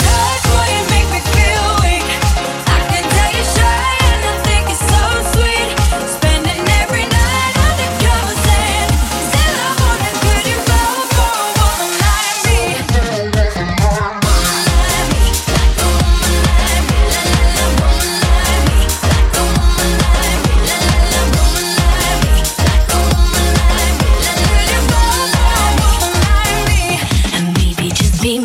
Genere: club, remix